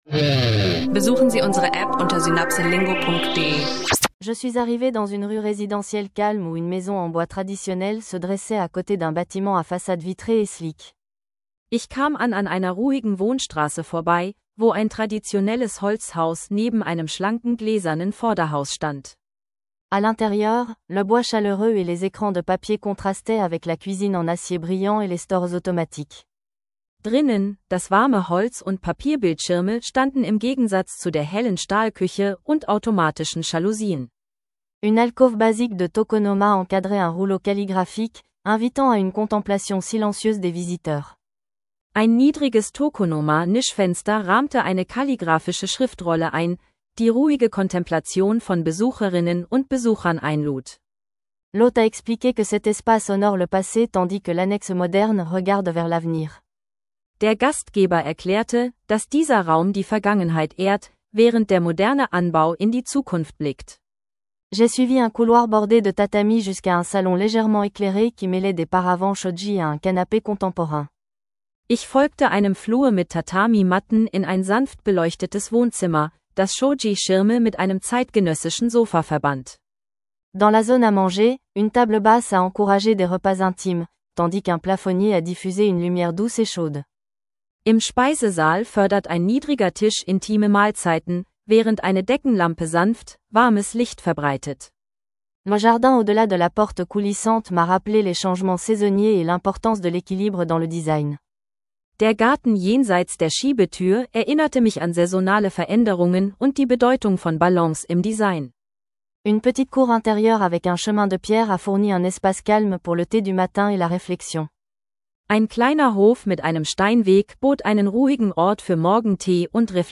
Entdecke Vokabeln und Phrasen rund um Hauskultur in Japan – interaktiver Audio-Sprachkurs für Anfänger und Fortgeschrittene.